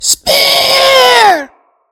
Audio / SE / Cries / SPEAROW.mp3